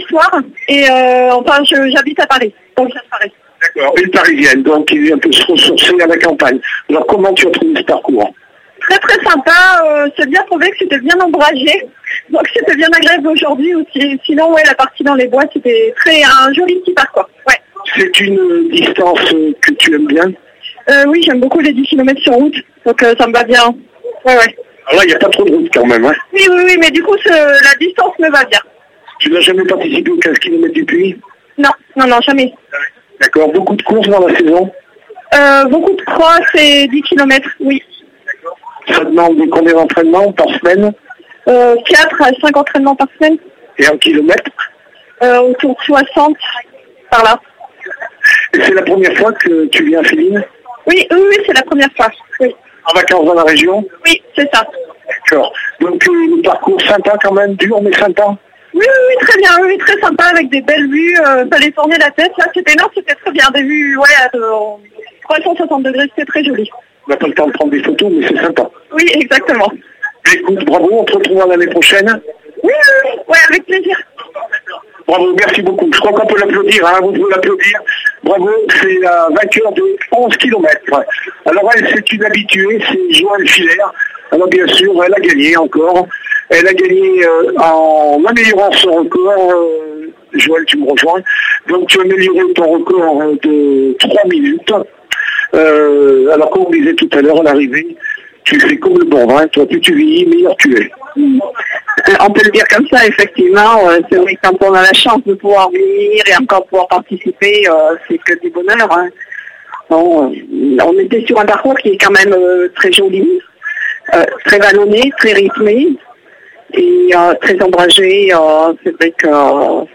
ITV-SOURCES-DE-LA-BORNE-PART1.mp3